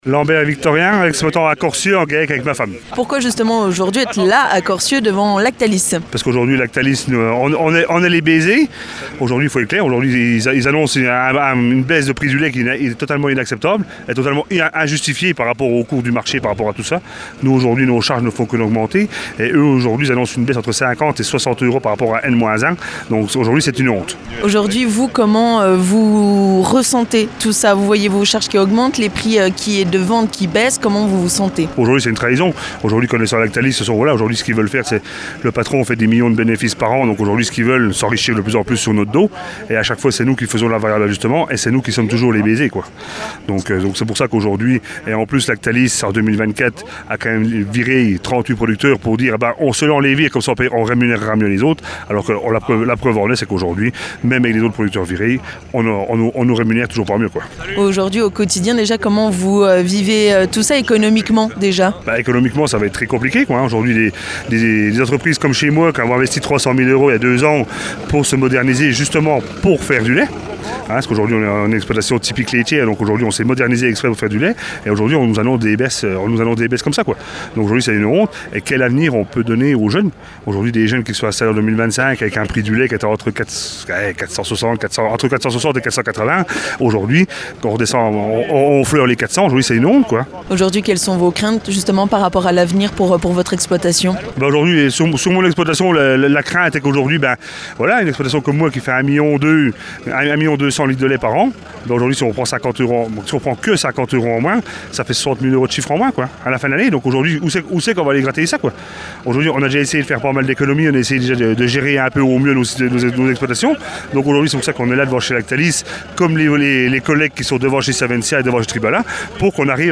Nous nous sommes rendus à Corcieux, devant Lactalis pour aller à la rencontre des producteurs laitiers présents.